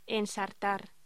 Locución: Ensartar
voz